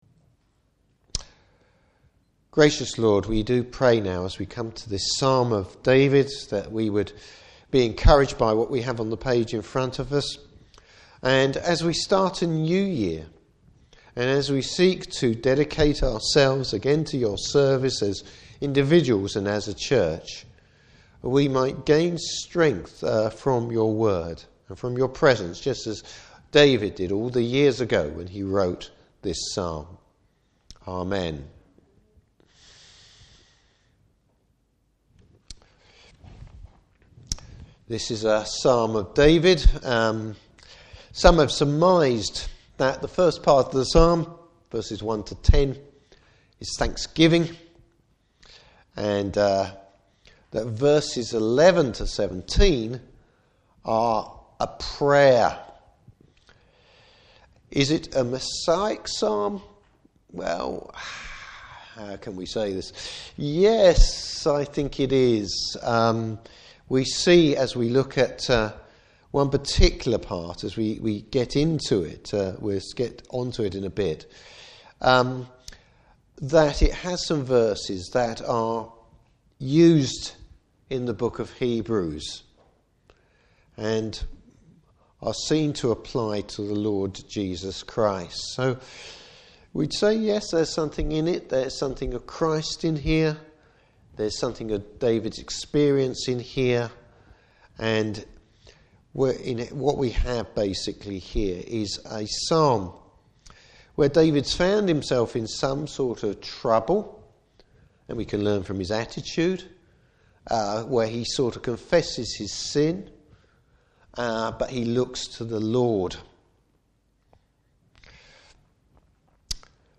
Series: New Years Sermon.
Service Type: Morning Service Having faith the Lord will act.